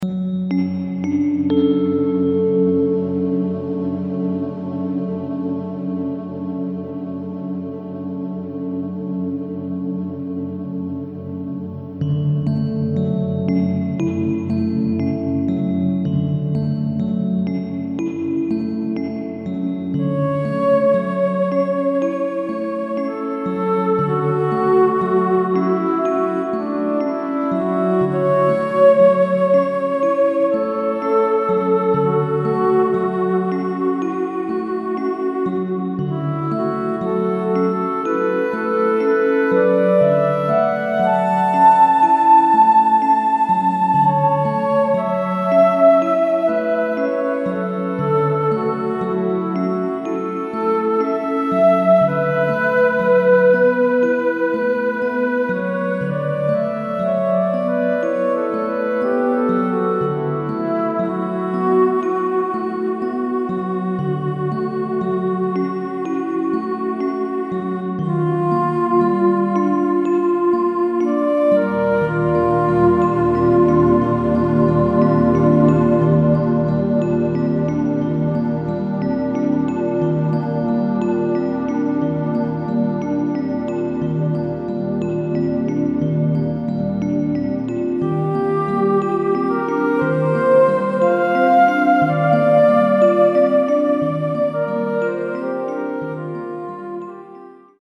A compilation of ambient electronic works.